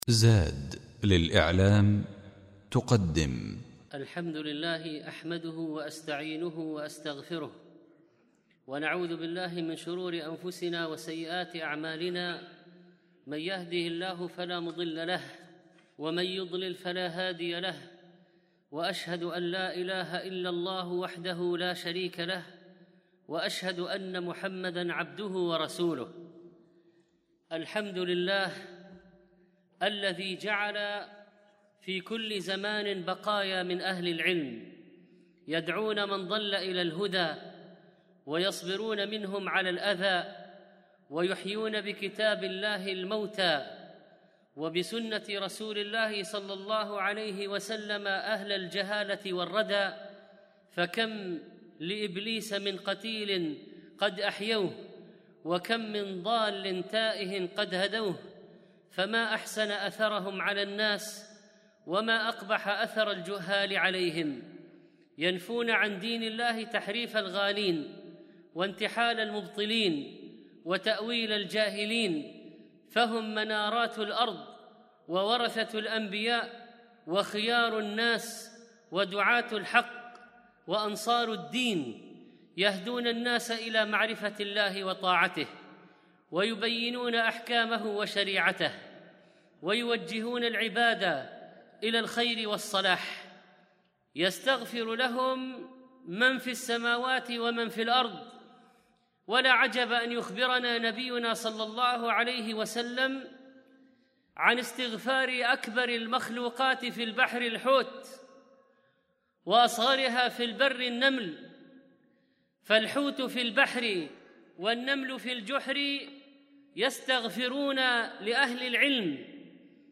الخطبة الأولى فضل العلماء وعظم أثر موتهم على الأمة